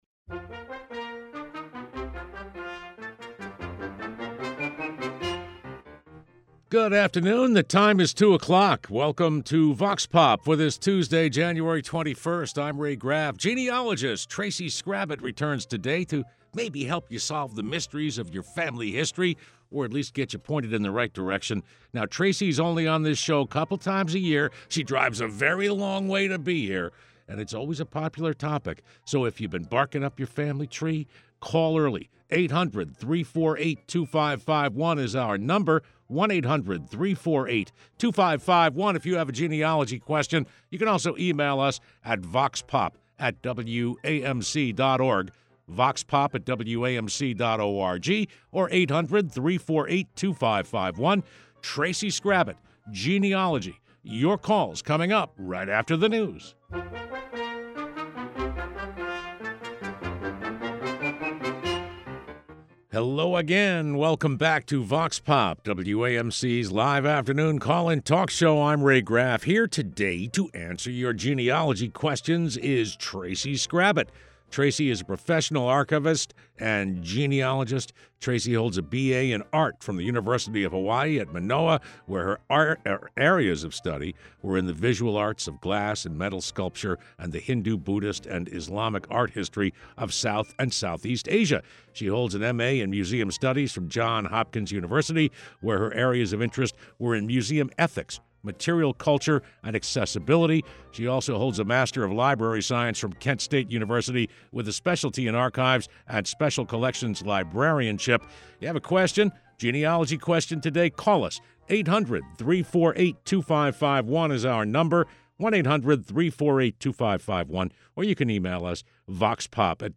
Vox Pop is WAMC's live call-in talk program.